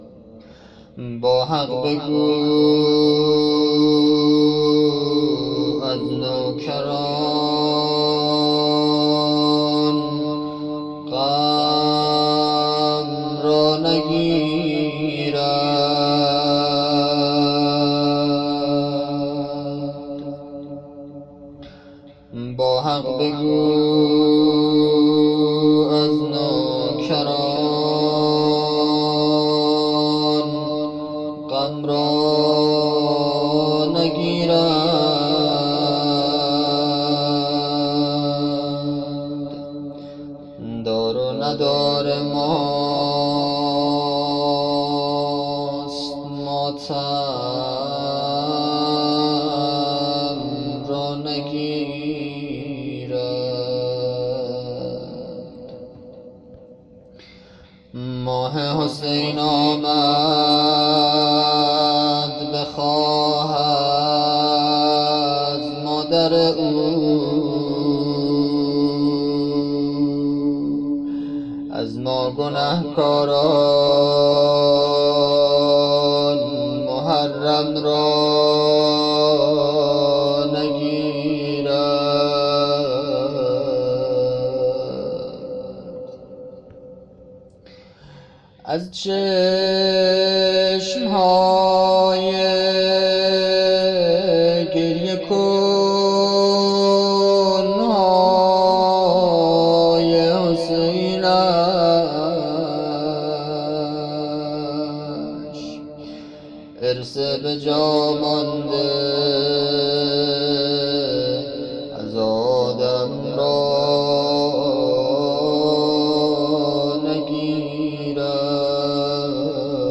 محرم 1400
مناجات